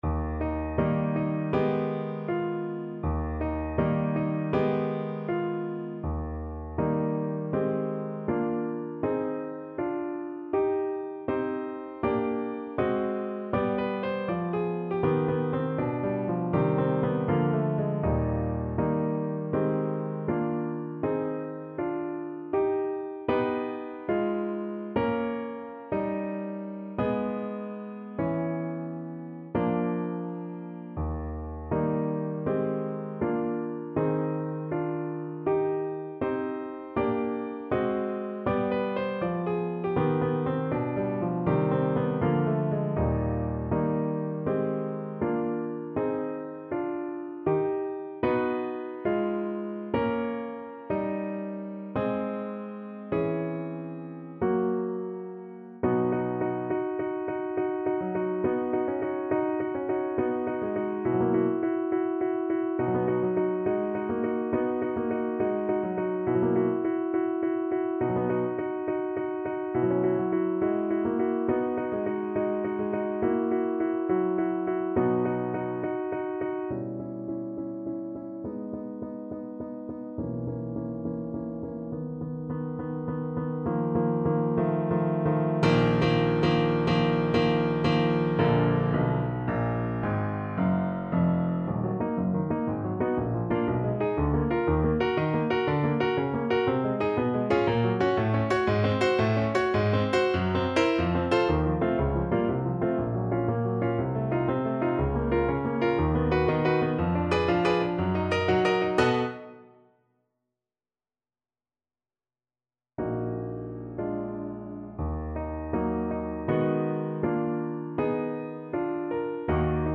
Moderato =80
Classical (View more Classical Saxophone Music)